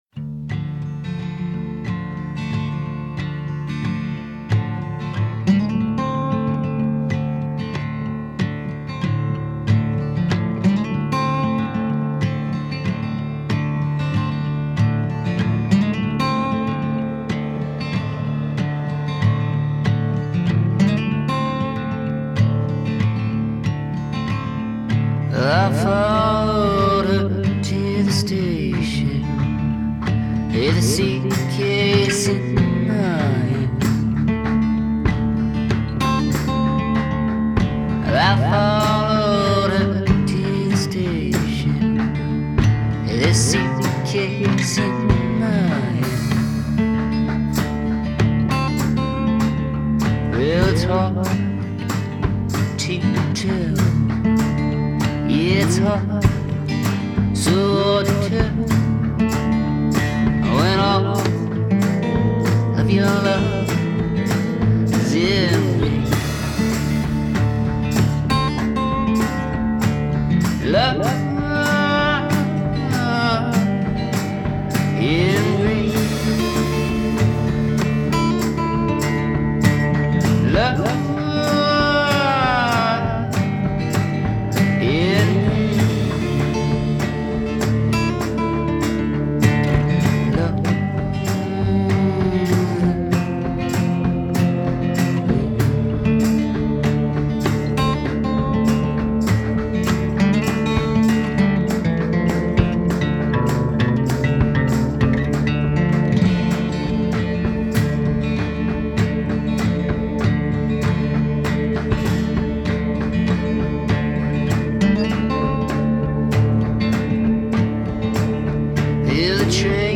blues